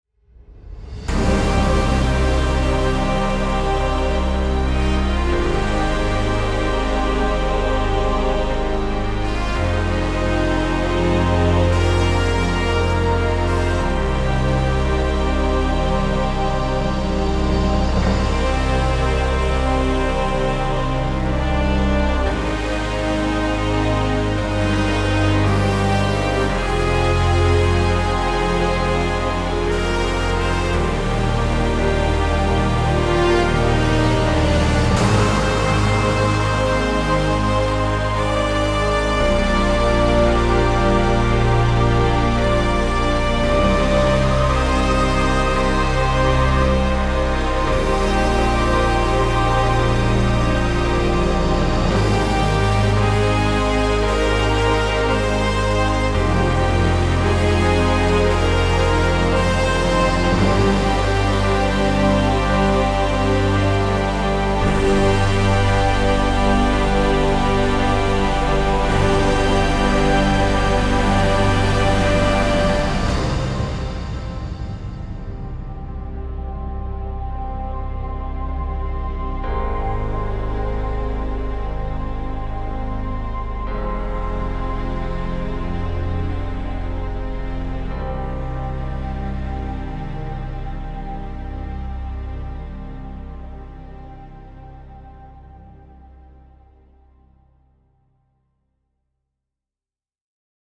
big no rhythm Version